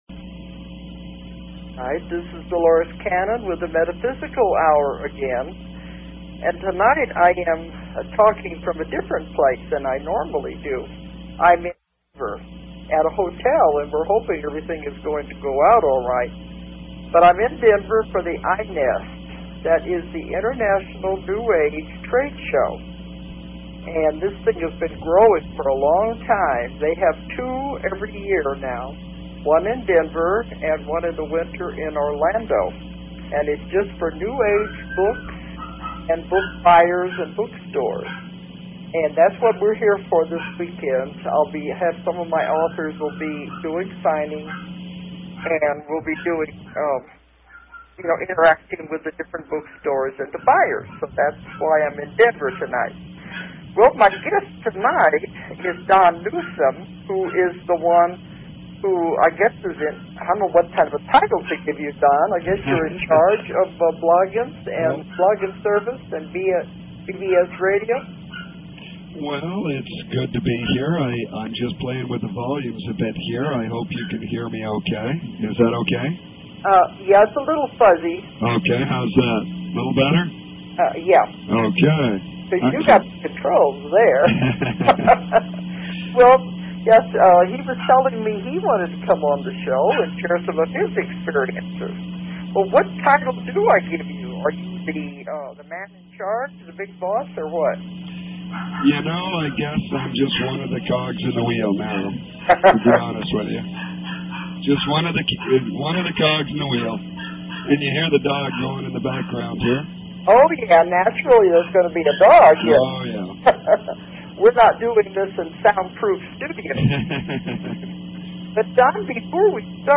Talk Show Episode, Audio Podcast, The_Metaphysical_Hour and Courtesy of BBS Radio on , show guests , about , categorized as
Special Guest